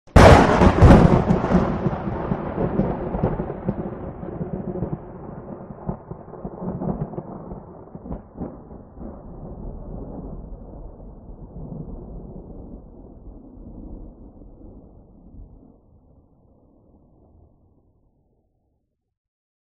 Thunderclap Efecto de Sonido Descargar
Thunderclap Botón de Sonido